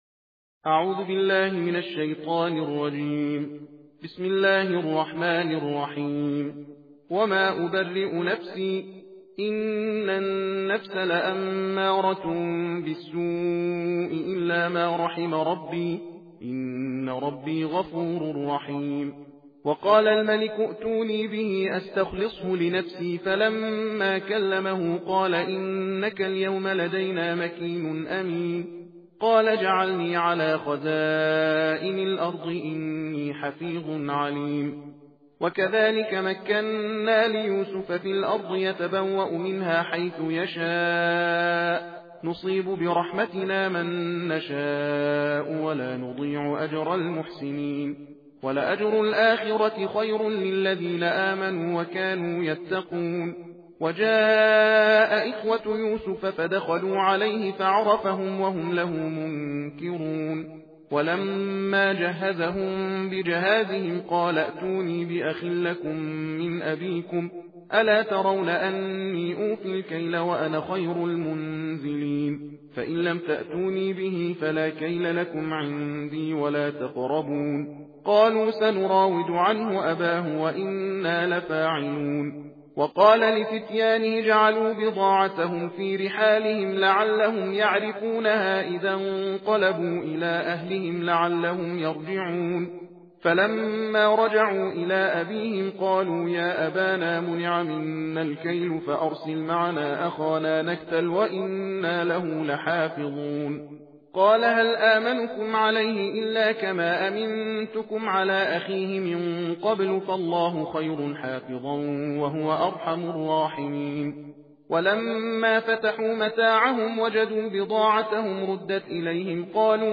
صوت/ تندخوانی جزء سیزدهم قرآن کریم